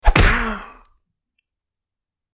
Tiếng Cú Đánh hạ gục kẻ thù Aaa….
Thể loại: Đánh nhau, vũ khí
tieng-cu-danh-ha-guc-ke-thu-aaa-www_tiengdong_com.mp3